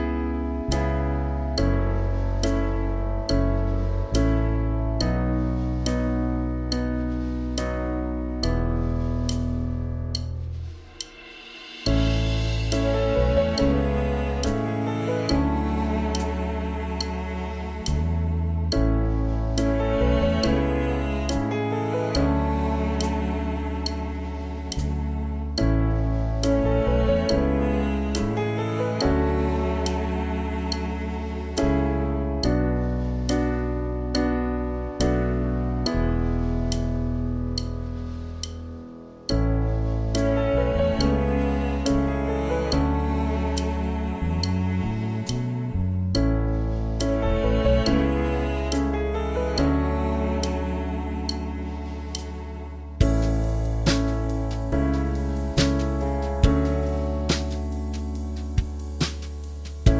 Minus Main Guitars For Guitarists 4:45 Buy £1.50